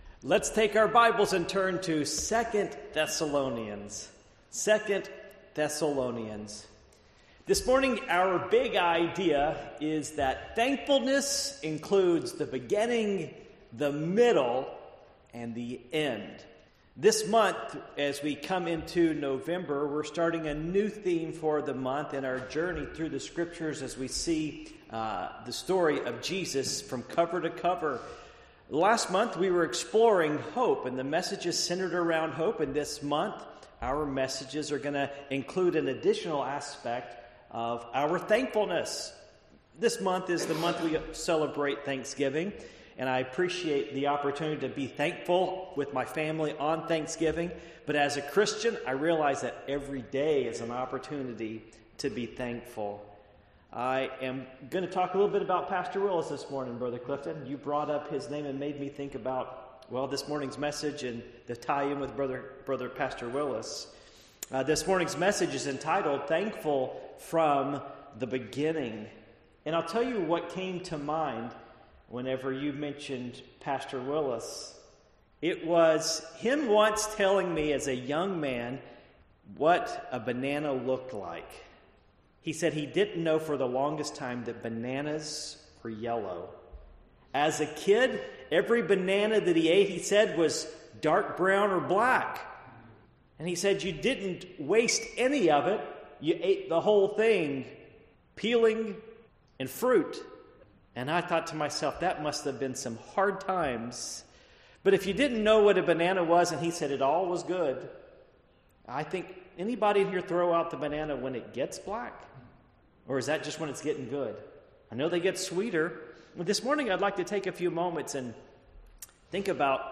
Passage: 2 Thessalonians 2:13-17 Service Type: Morning Worship